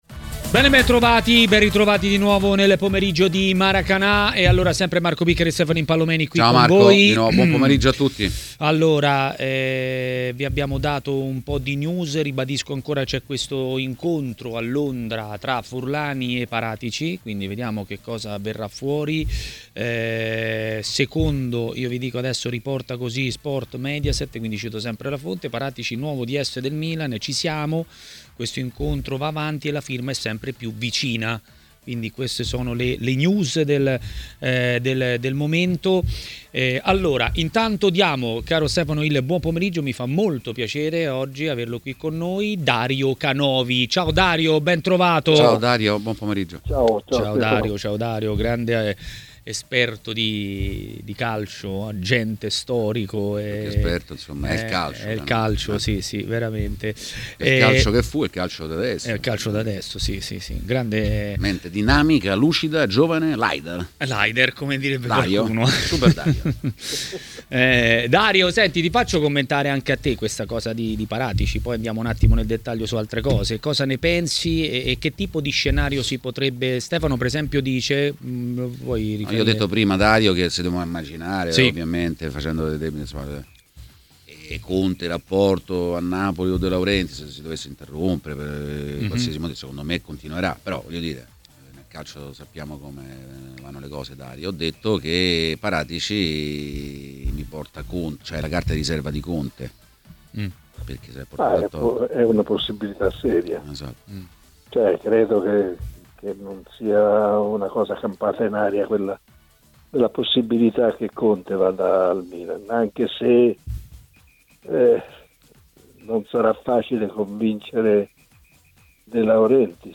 è intervenuto in diretta a TMW Radio, durante Maracanà.